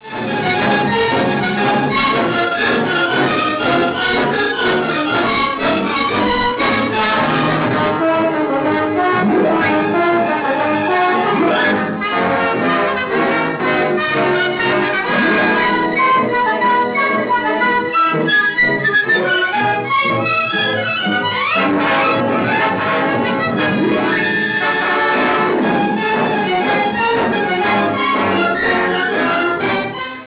Colonna sonora
Original track music